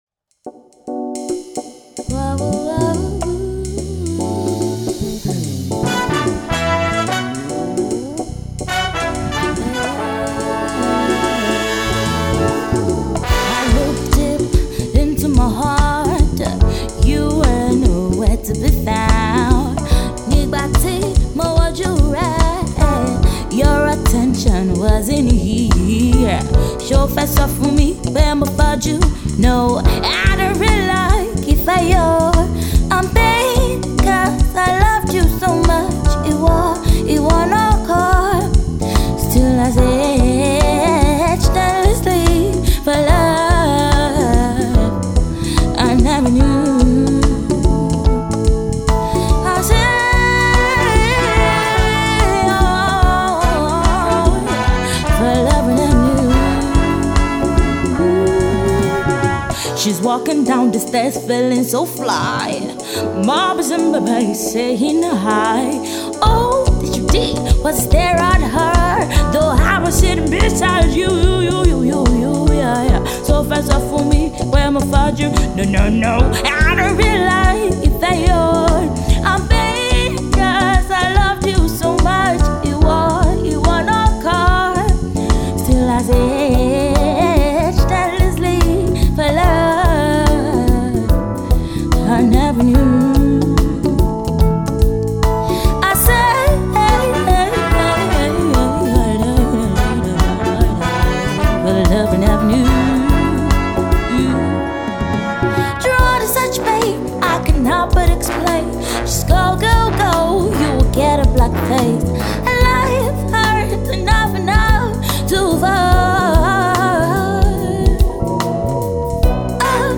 is a 17 year old Lagos-based Nigerian Afro-soul Singer.
sensational Pop/Reggae song about love gone sour